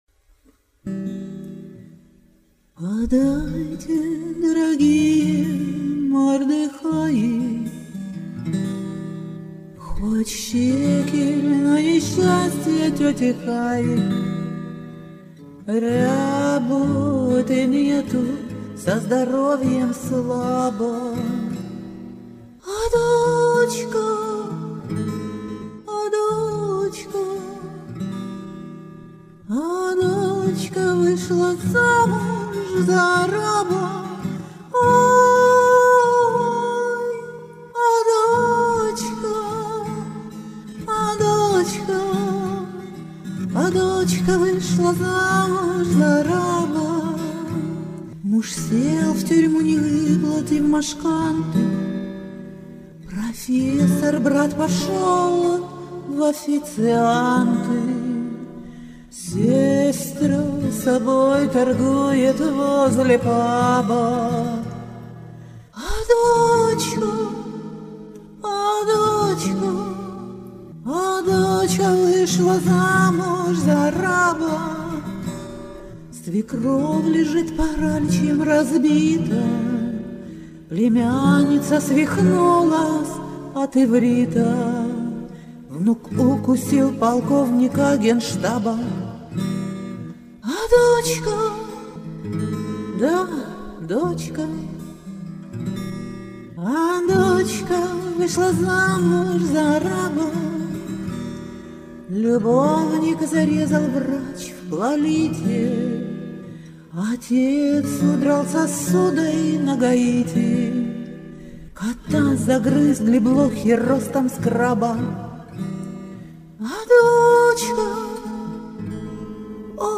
Шансон
Записала их по-памяти в домашних условиях.